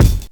INSKICK03 -R.wav